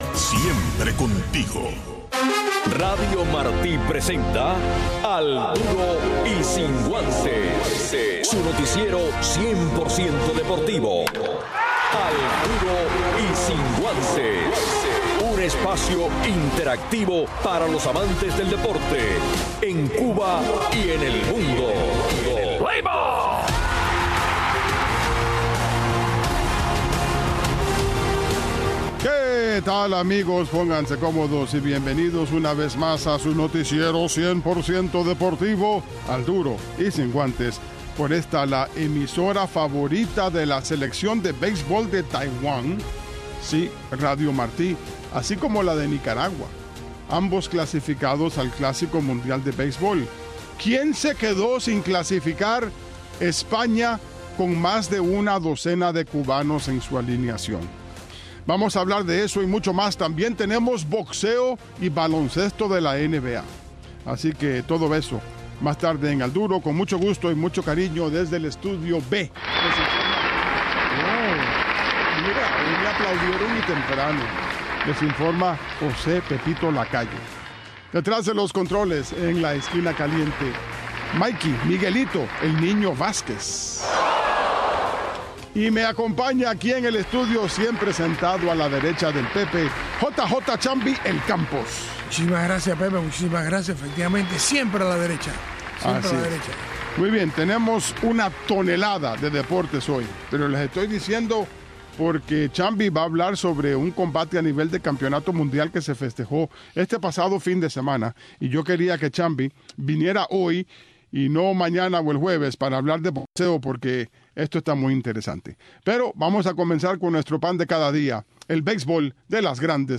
Un resumen deportivo en 60 minutos conducido